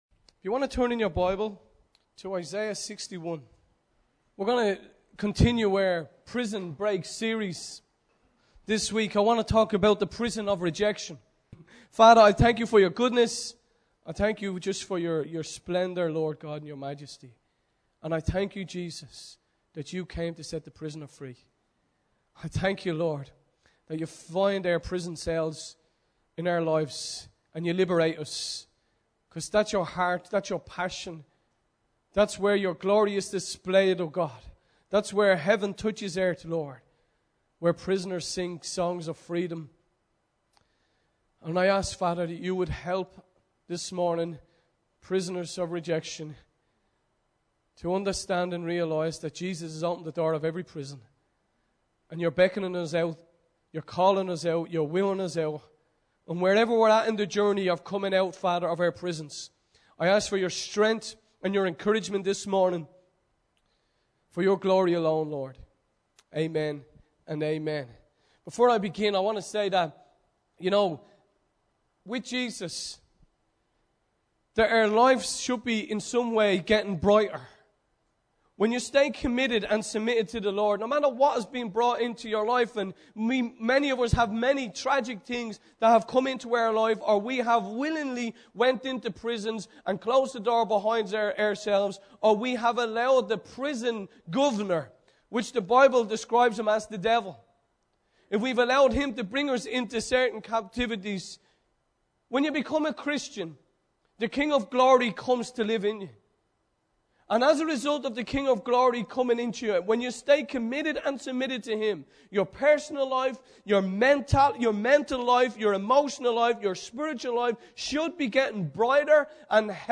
Recorded Live at Liberty on 16 November 2008